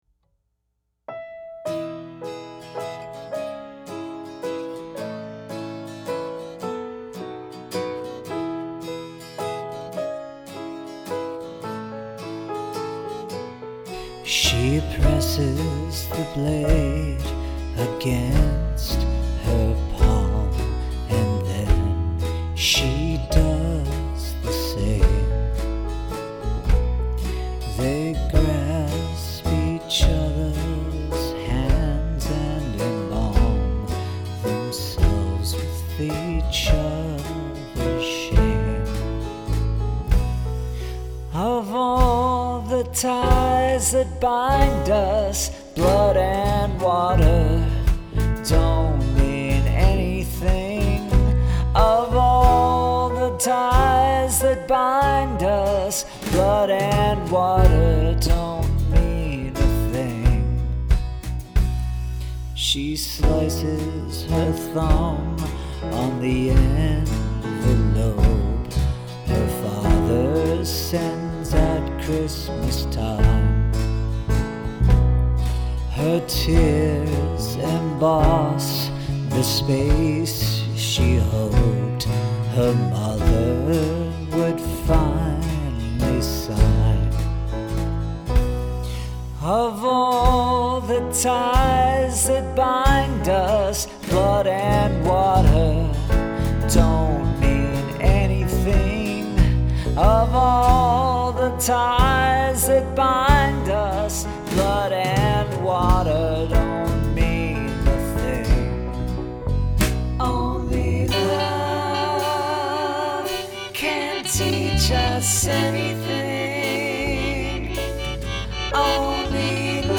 Must include three different tempos
3/4 q=108